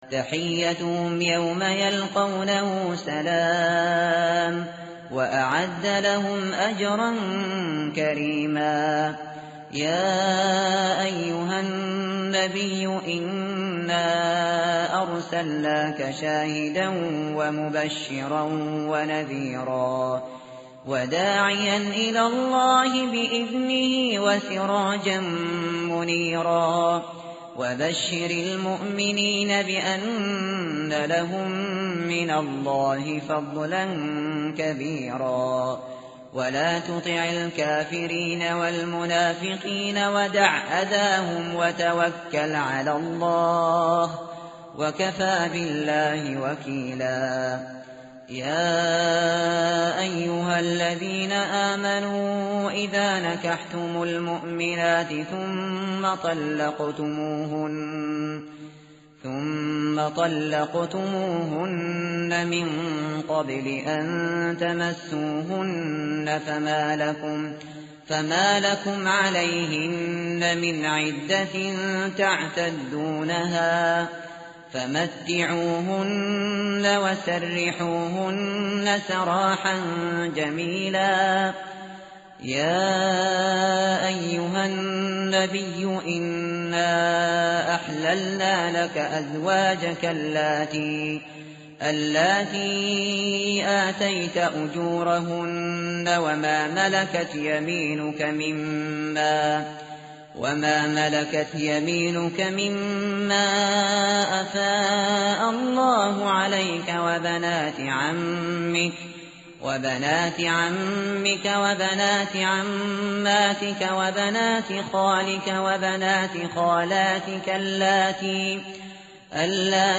متن قرآن همراه باتلاوت قرآن و ترجمه
tartil_shateri_page_424.mp3